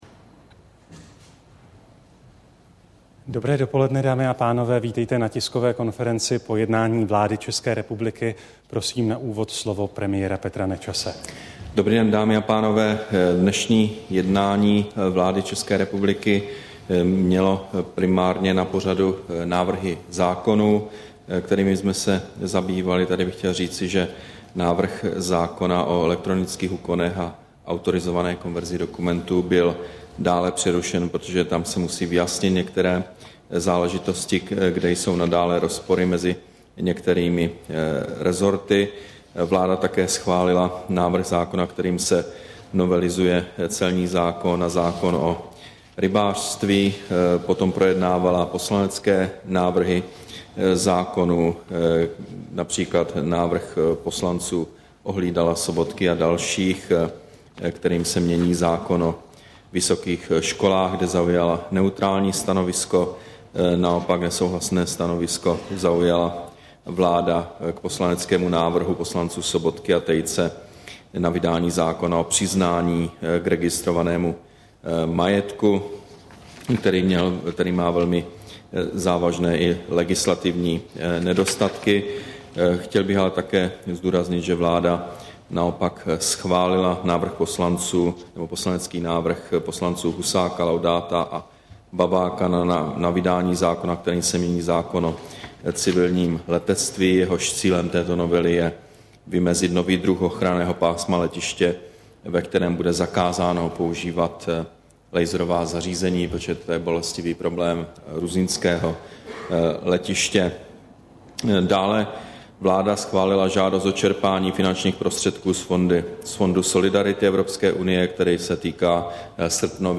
Tisková konference po jednání vlády, 6. října 2010